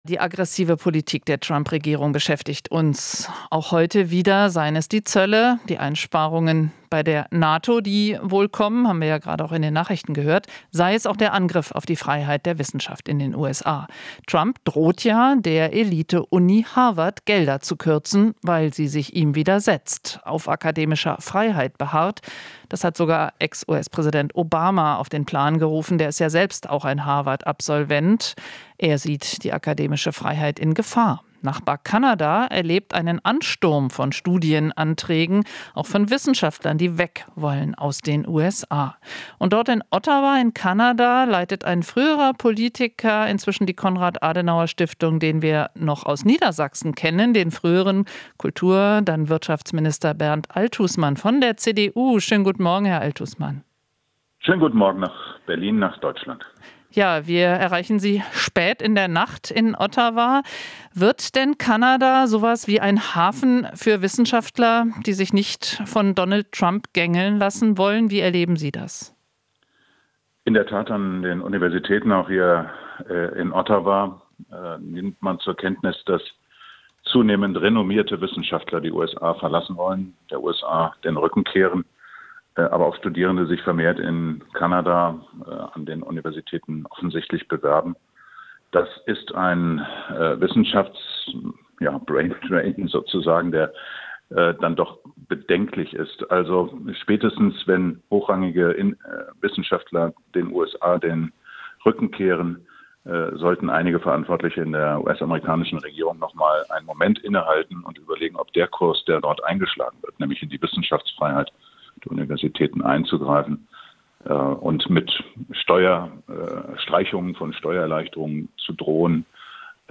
Interview - Althusmann: Trumps Politik führt zu Geschlossenheit in Kanada